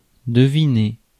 Ääntäminen
Vaihtoehtoiset kirjoitusmuodot (vanhentunut) æstimate Synonyymit estimation appraisal appraise give appreciate guess reckon calculation Ääntäminen US US Tuntematon aksentti: IPA : /ˈɛstɨmɨt/ IPA : /ˈɛstɨˌmeɪ̪t/